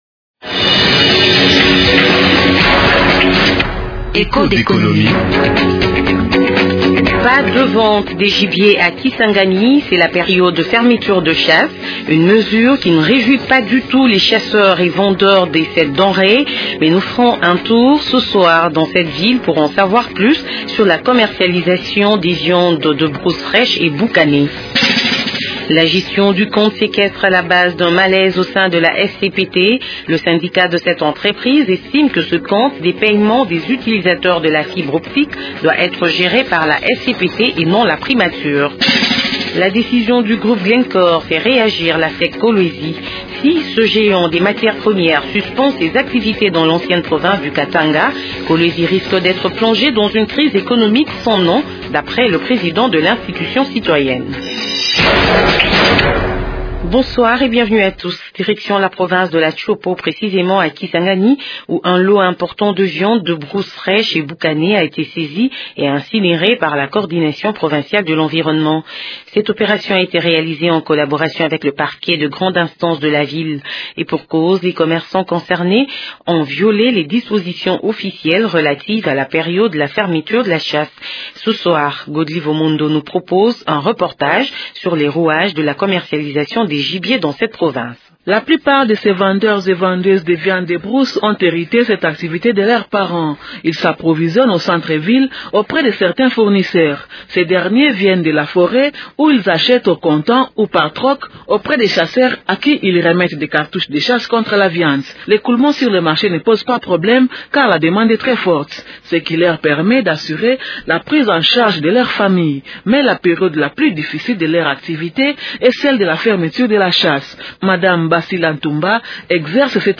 Nous vous propose un reportage sur les rouages de la commercialisation des gibiers dans cette province.